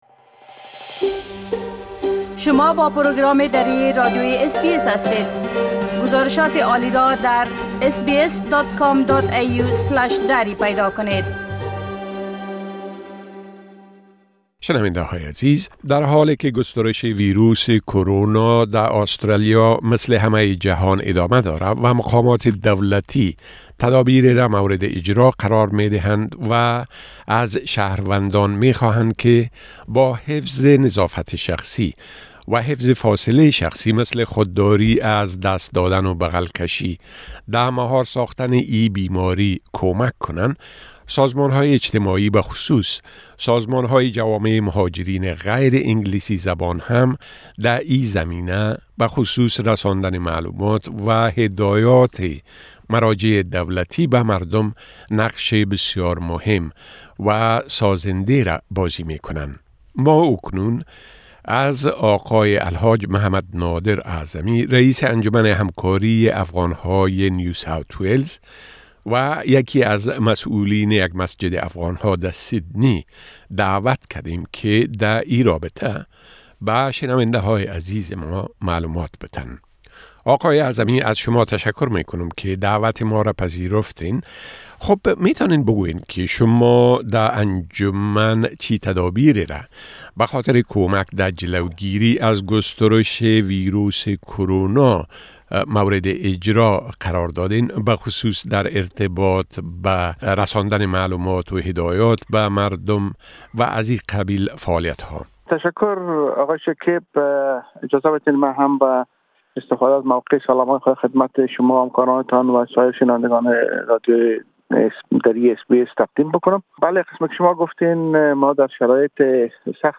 به گفت‌وگوی اس‌بی‌اس دری